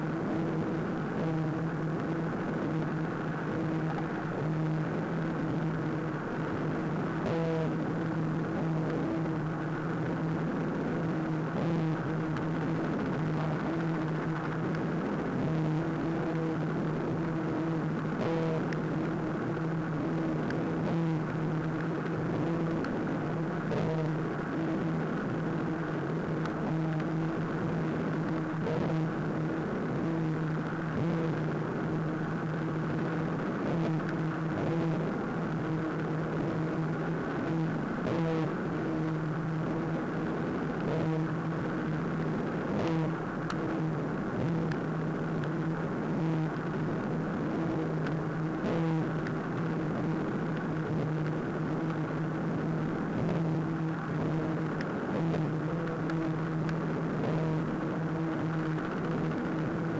Opsanus tau chorus spectrogram Chorus Spectrogram. An example of a typical oyster toadfish chorus recorded 15 June 2001 on the dock at Woods Hole, MA
Listen an Oyster toadfish chorus